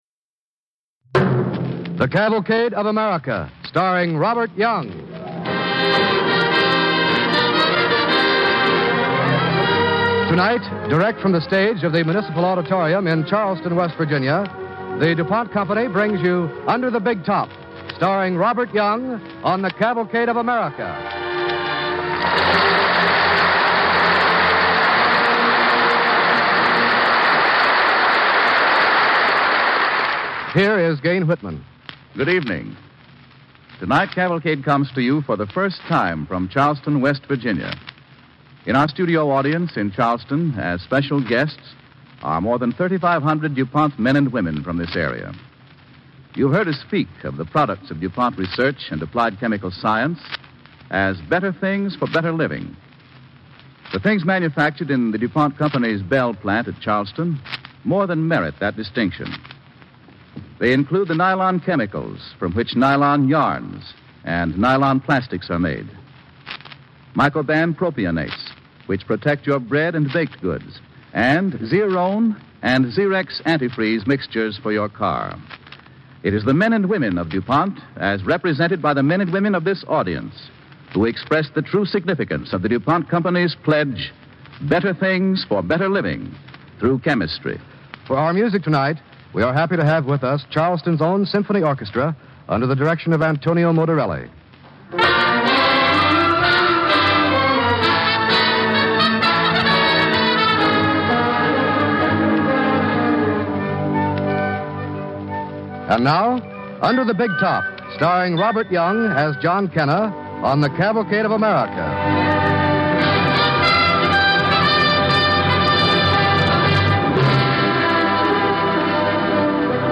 starring Robert Young and Roy Atwell
Cavalcade of America Radio Program